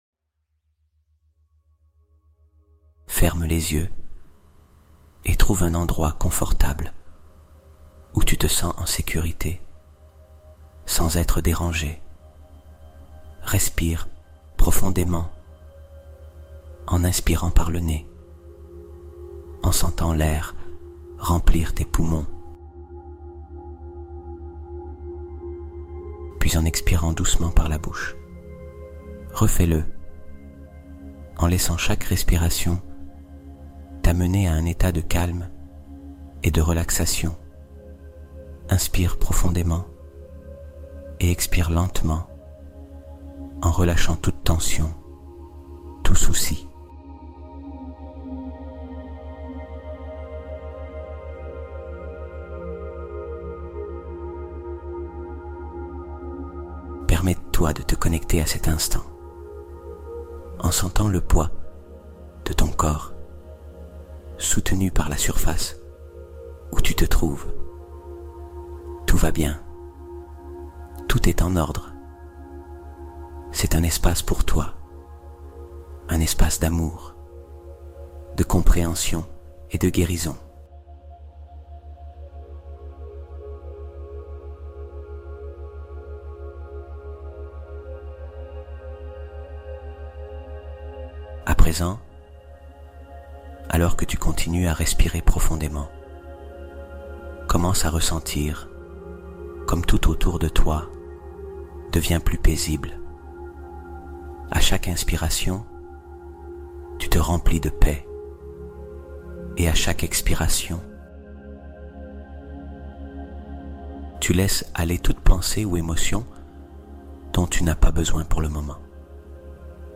Renaître Spirituellement : Reconnecte-toi À Ton Enfant Intérieur (Méditation de Guérison Profonde)